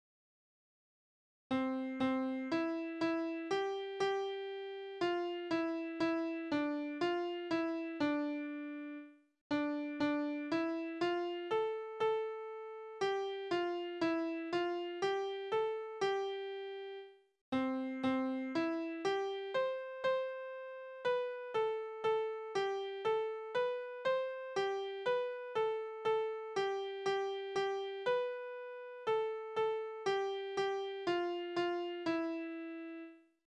Balladen: Der Soldat erschießt seine ungetreue Liebste
Tonart: C-Dur
Taktart: 4/4
Tonumfang: Oktave
Besetzung: vokal
Anmerkung: Vortragsbezeichnung: Marschmäßig